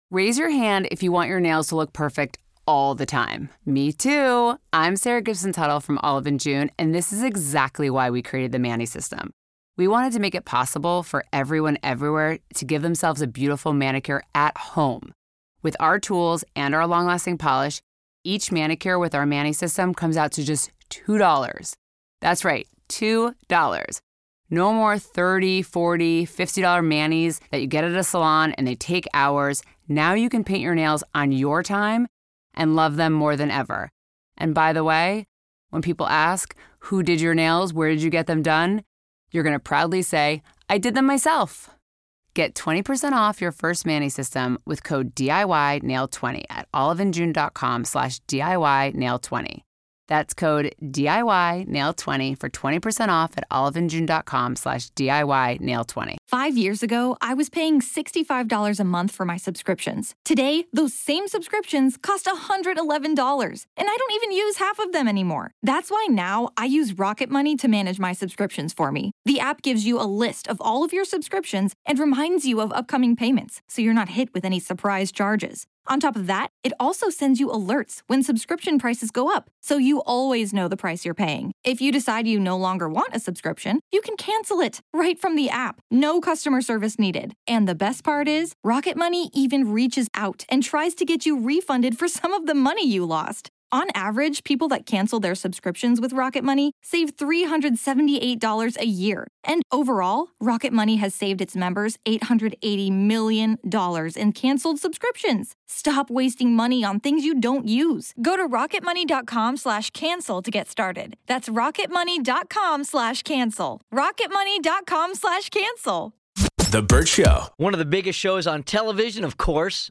Vault: Interview With Kelly Clarkson